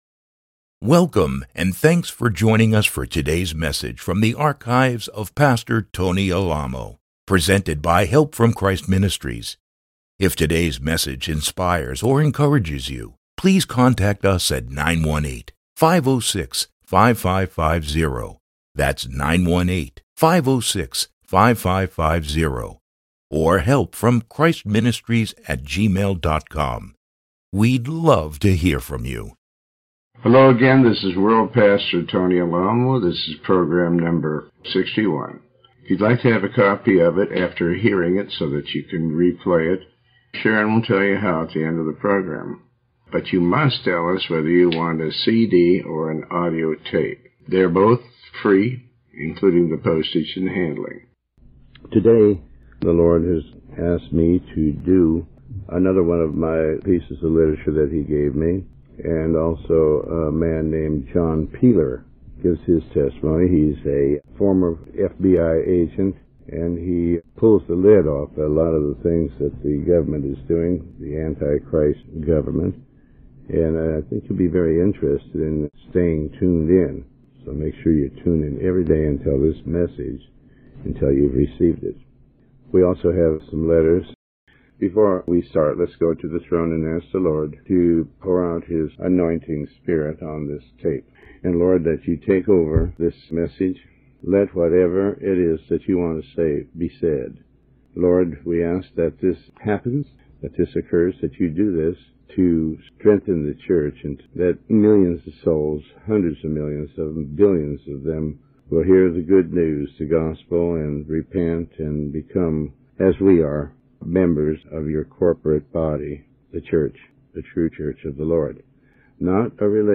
Sermon 61